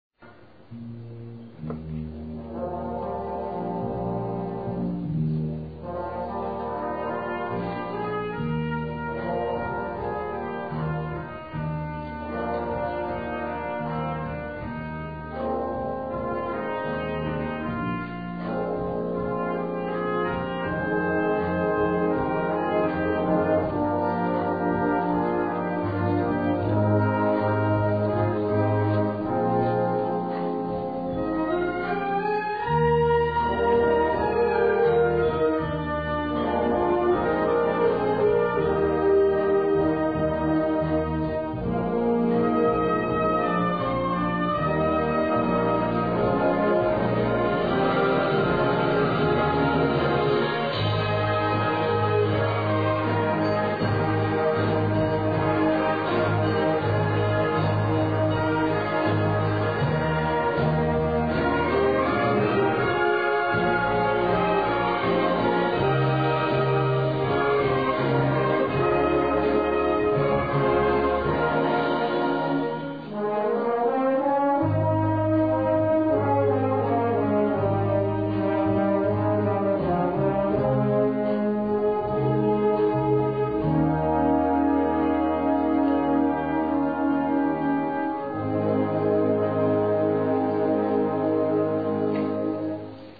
Concert de printemps 2003
Le dimanche 6 avril, l'espace François Mitterrand a accueilli le concert de printemps 2003 de l'école de musique d'Achicourt.
L'harmonie d'Achicourt 2003